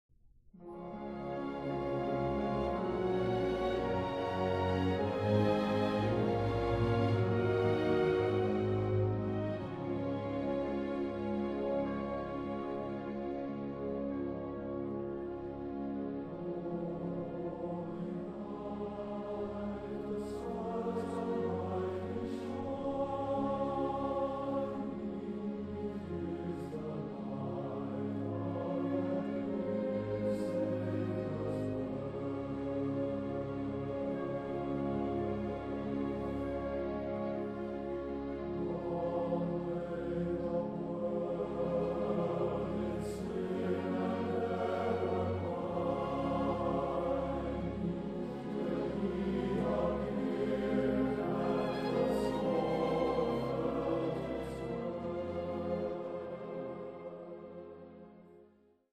傳統的聖誕音樂